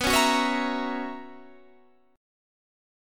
BM7sus2sus4 chord